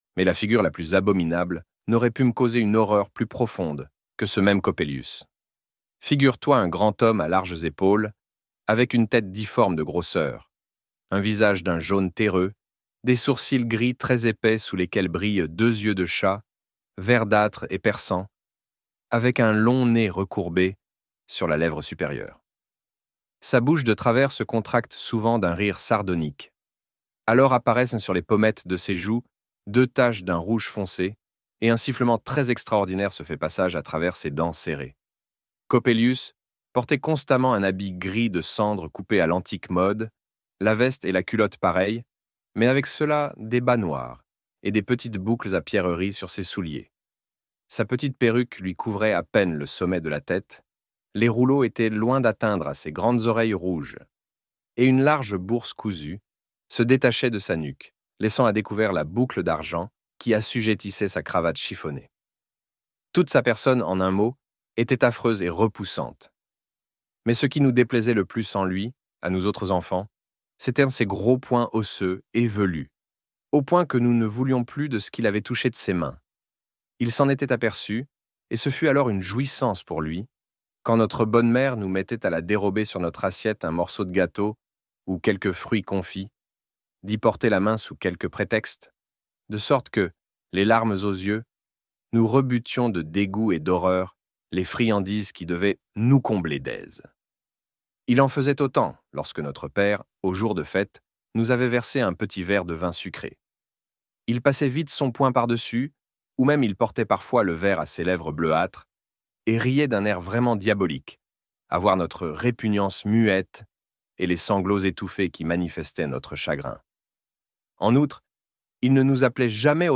L'Homme au sable - Livre Audio